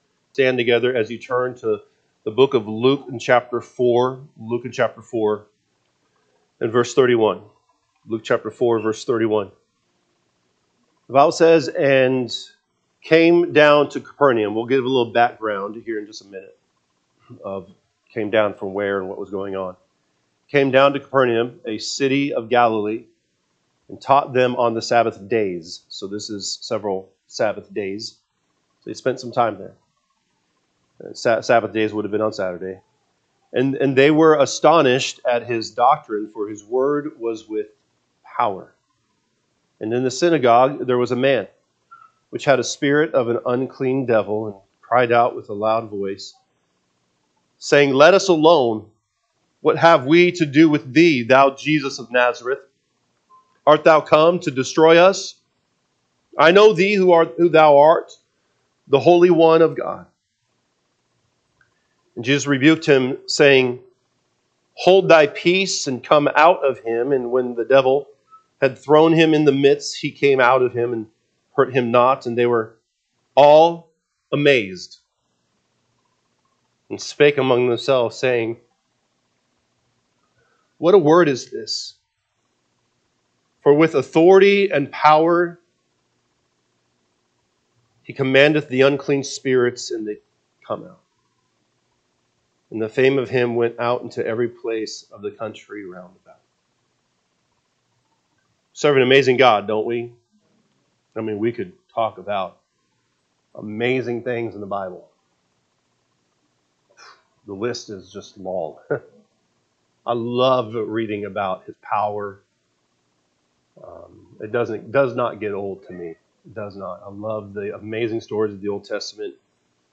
August 3, 2025 am Service Luke 4:31-37 (KJB) 31 And came down to Capernaum, a city of Galilee, and taught them on the sabbath days. 32 And they were astonished at his doctrine: for his wo…
Sunday AM Message